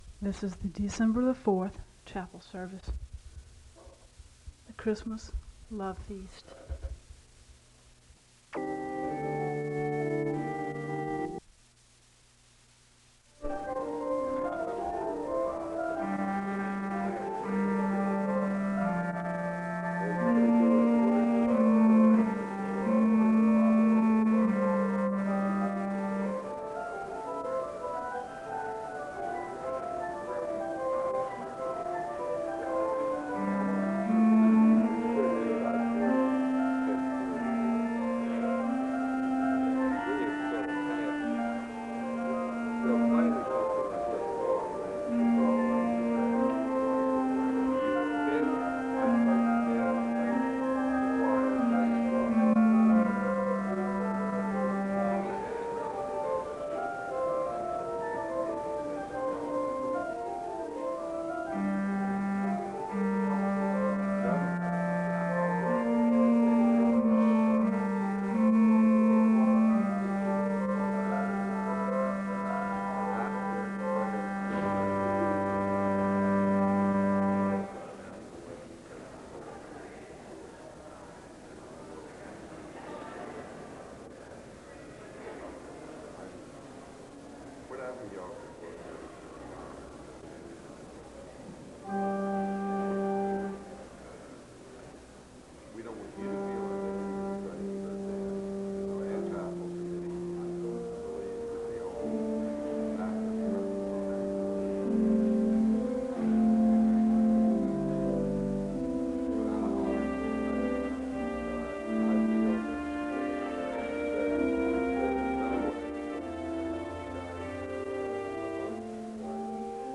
File Set | SEBTS_Event_Moravian_Lovefeast_1984-12-04.wav | ID: fbdf352b-1b7b-49a7-b99e-3b5953d5d8cb | 蹄兔